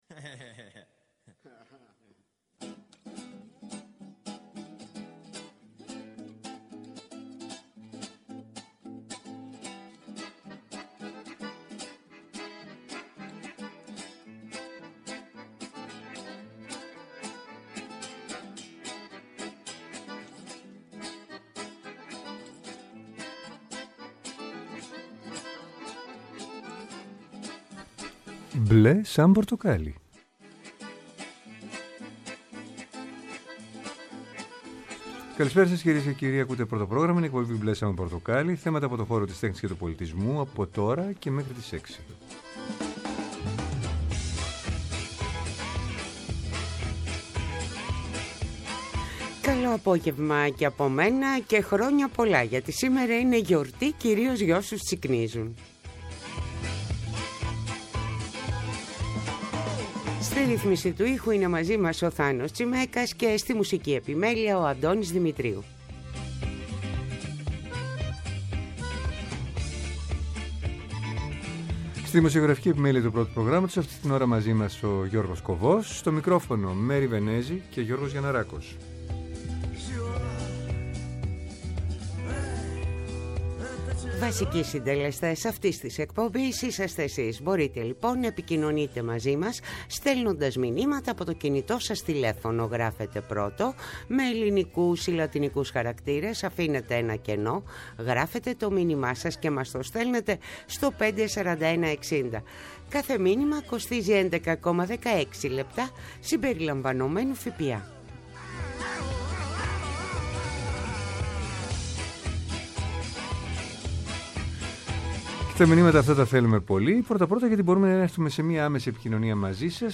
“Μπλε σαν πορτοκάλι”. Θέατρο, κινηματογράφος, μουσική, χορός, εικαστικά, βιβλίο, κόμικς, αρχαιολογία, φιλοσοφία, αισθητική και ό,τι άλλο μπορεί να είναι τέχνη και πολιτισμός, καθημερινά από Δευτέρα έως Πέμπτη 5-6 το απόγευμα από το Πρώτο Πρόγραμμα. Μια εκπομπή με εκλεκτούς καλεσμένους, άποψη και επαφή με την επικαιρότητα.